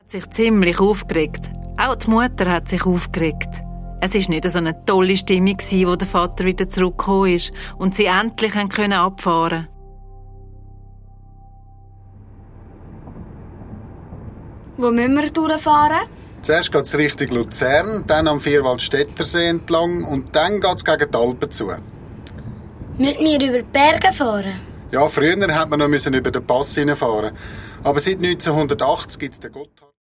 Hörspiel-Album